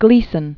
(glēsən), Herbert John Known as "Jackie." 1916-1987.